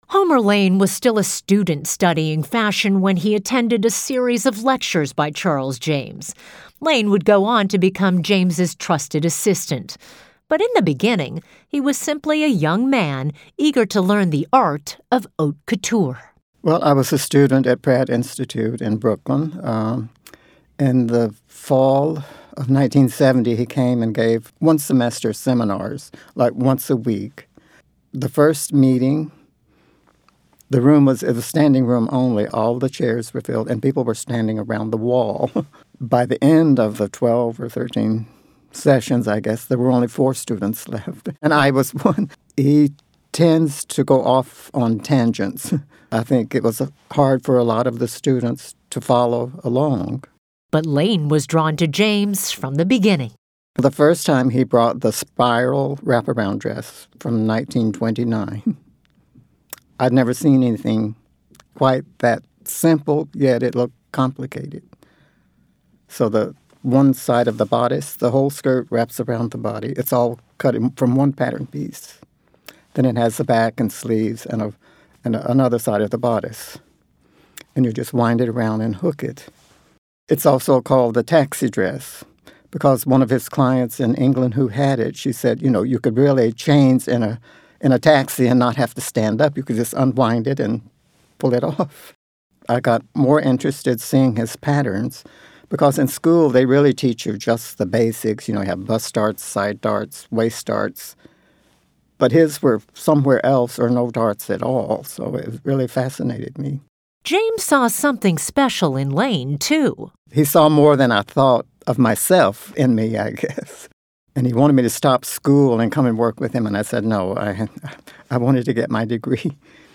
Recalling Charles James: Audio Interviews
The interviews are chronologically ordered according to the date each subject knew Charles James.